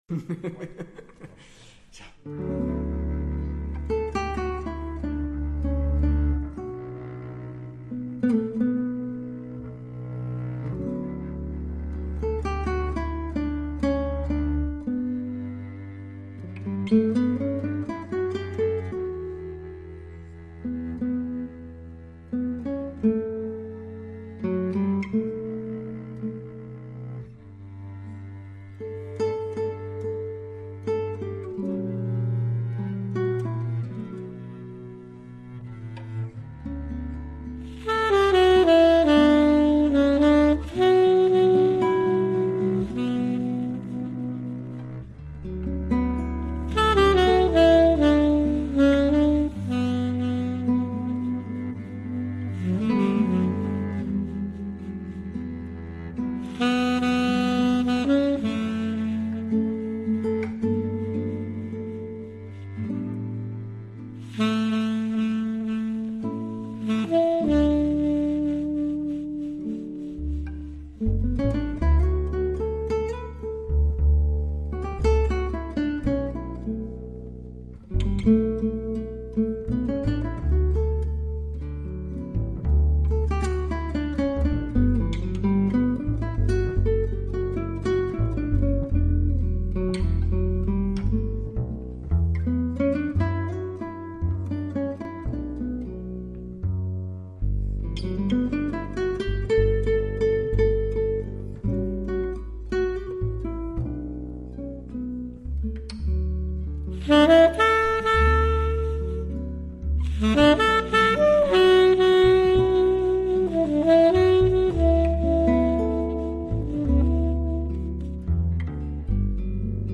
saxophone
acoustic bass
guitar
drums